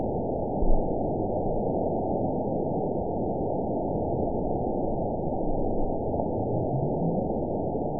event 920420 date 03/24/24 time 05:37:15 GMT (1 year, 1 month ago) score 9.54 location TSS-AB02 detected by nrw target species NRW annotations +NRW Spectrogram: Frequency (kHz) vs. Time (s) audio not available .wav